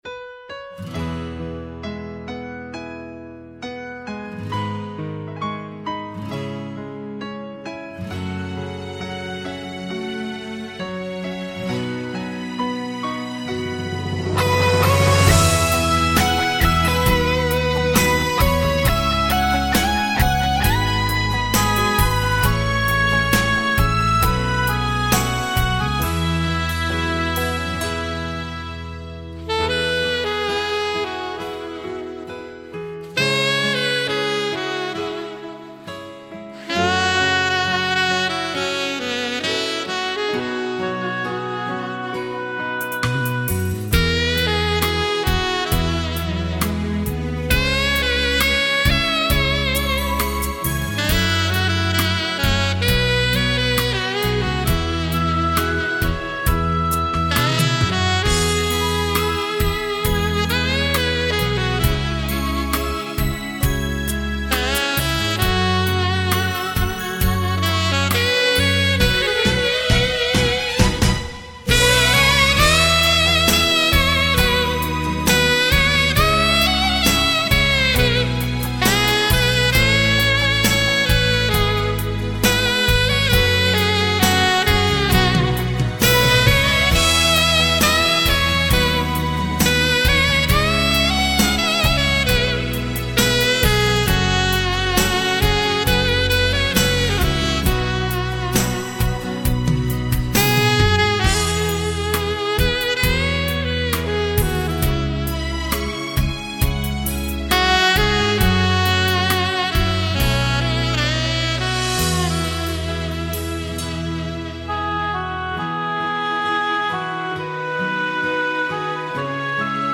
萨克斯演奏
冠军台语歌曲 首首收入